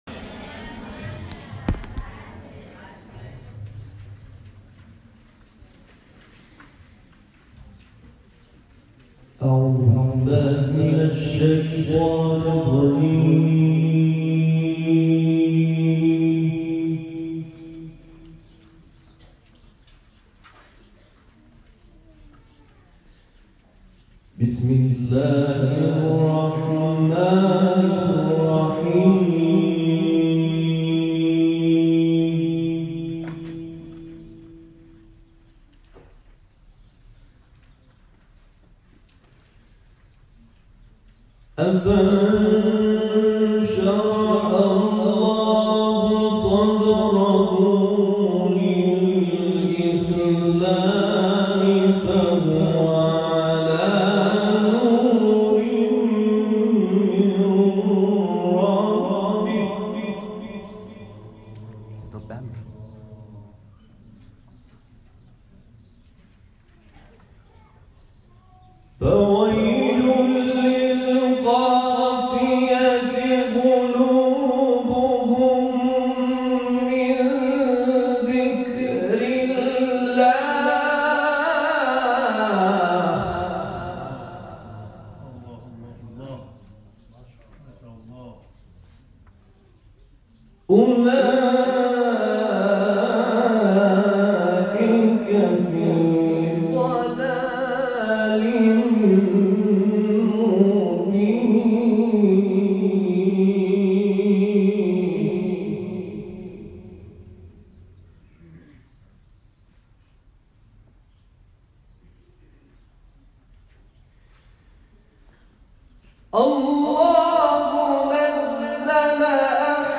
تلاوت
در دبیرستان شبانه‌روزی عفاف عنبرآباد